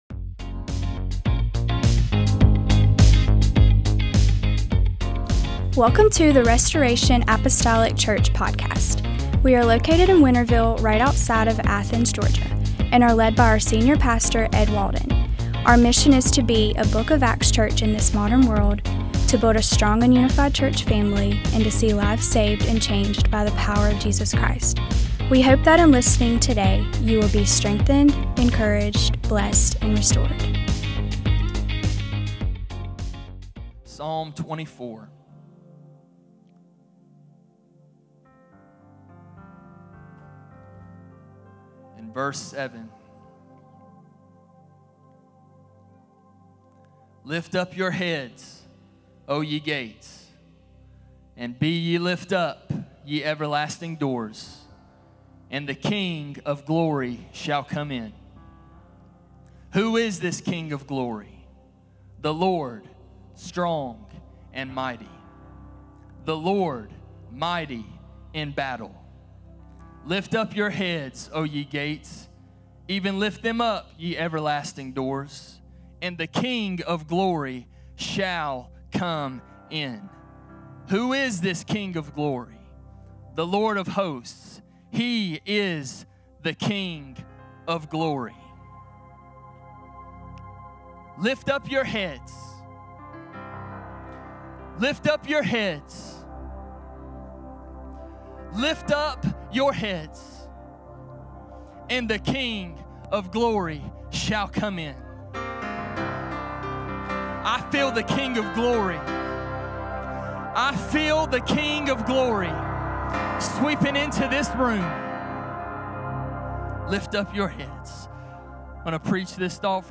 Sunday Service - 09/14/2025 - Assist.